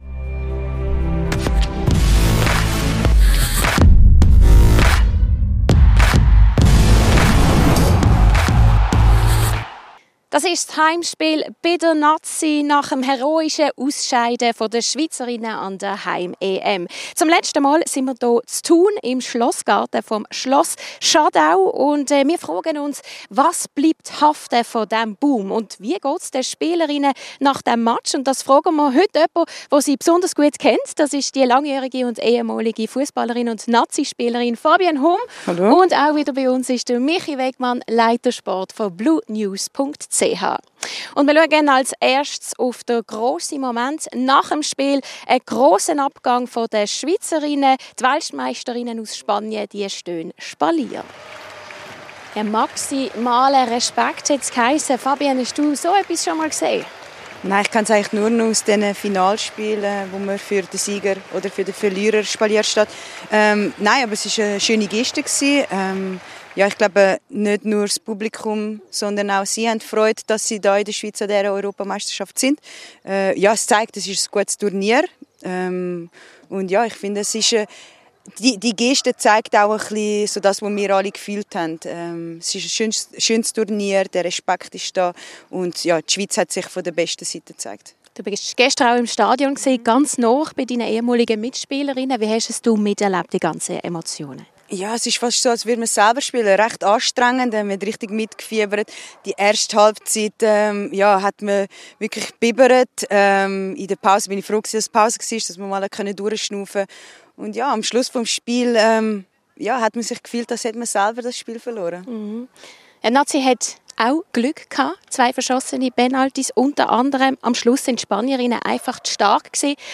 Heimspiel ⎥ Der Fussball-Talk Heimspiel bei der Frauen-Nati | Episode 7 | Herzen und Respekt erobert Play episode July 19 24 mins Bookmarks Episode Description 19.